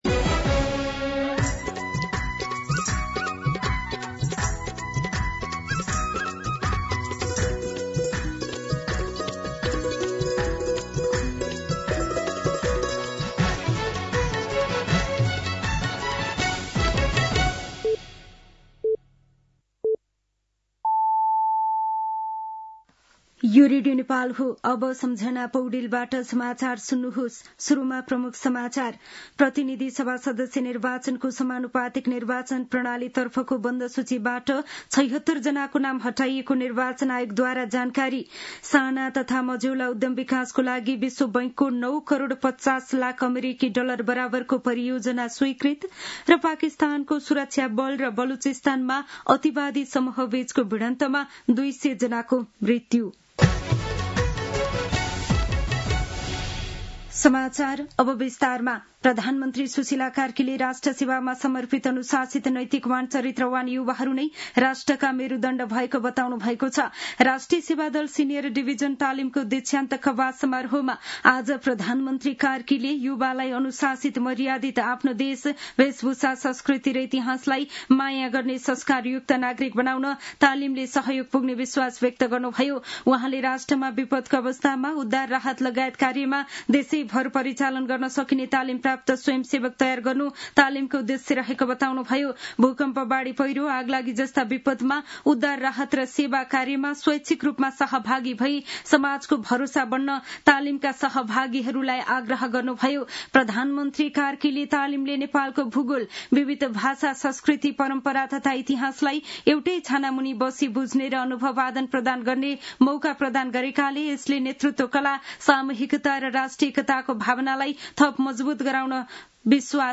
दिउँसो ३ बजेको नेपाली समाचार : २० माघ , २०८२